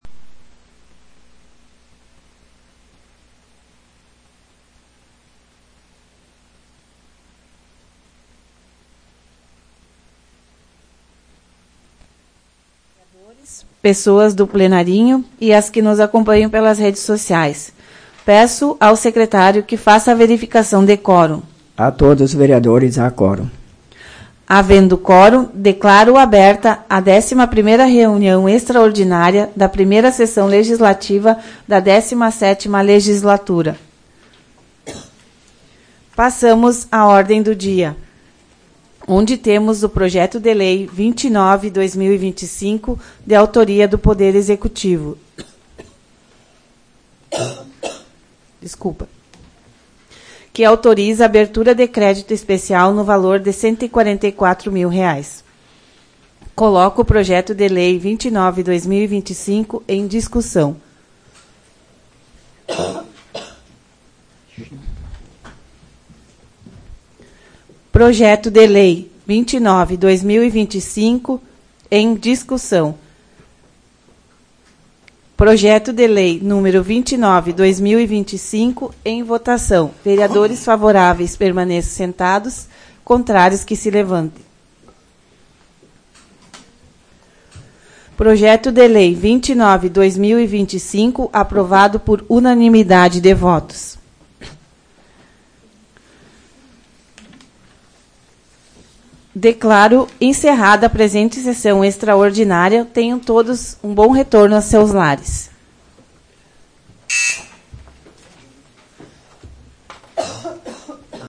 Áudio da 11ª Sessão Plenária Extraordinária b da 17ª Legislatura, de 22 de abril de 2025